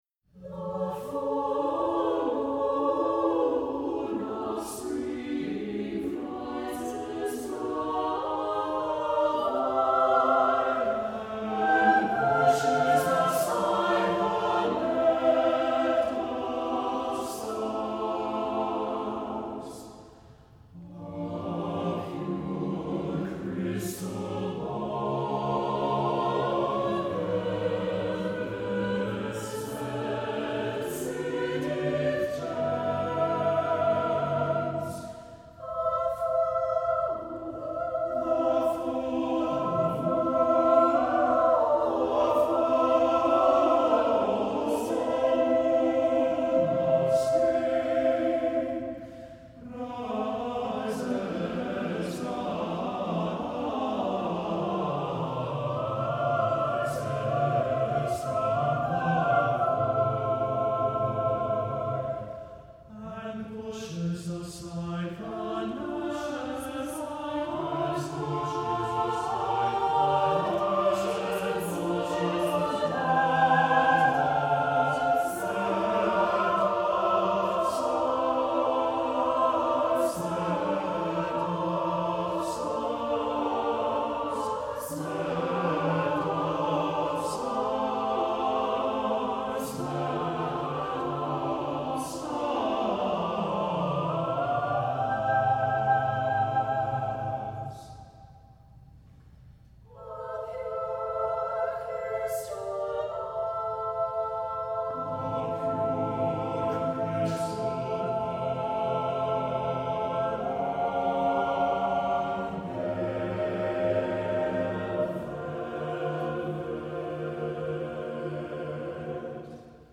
Voicing: SSATBB